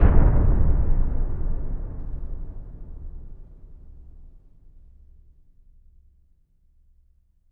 LC IMP SLAM 2A.WAV